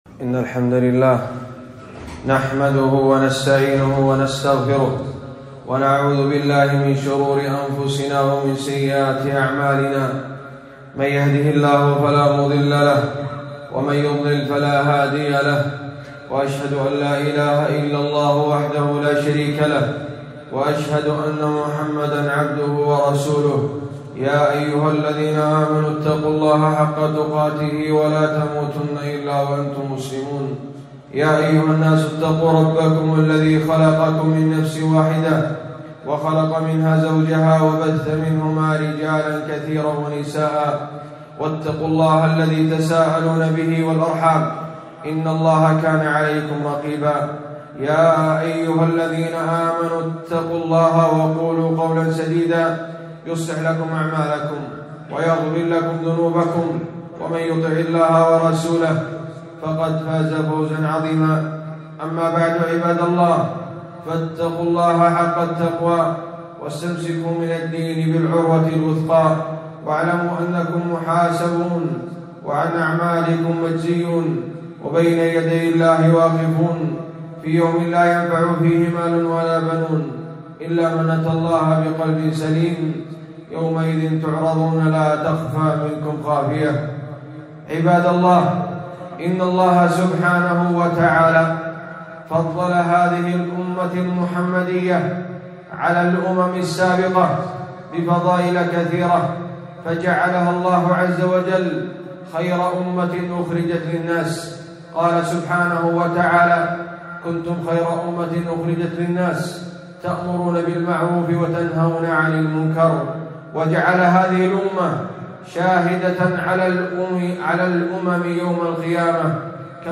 خطبة - من أحكام يوم الجمعة